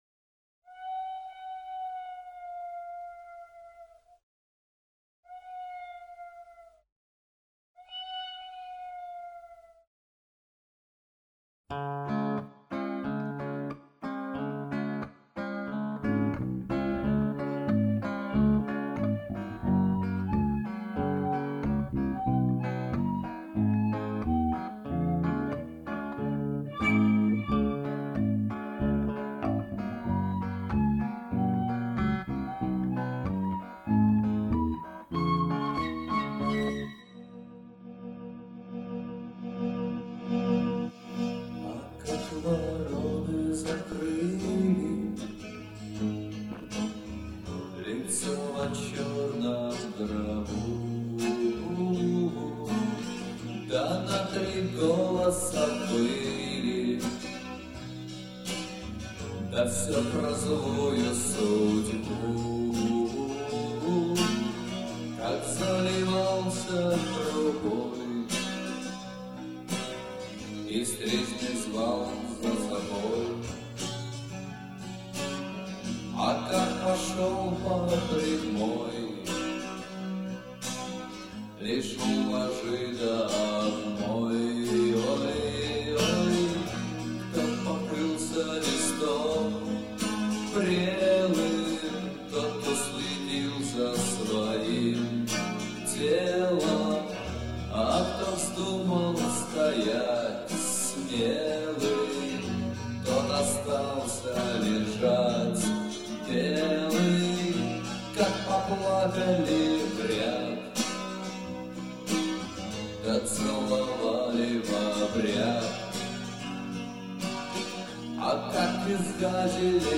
Семипалатинск, реставрированные записи - mp3.